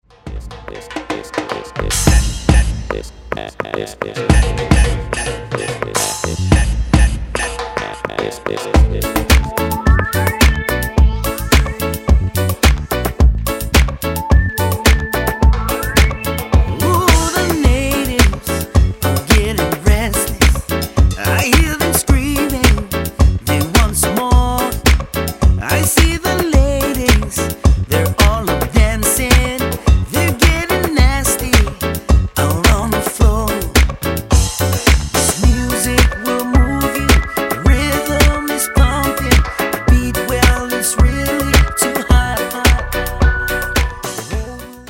Genre: Reggae, contemporary.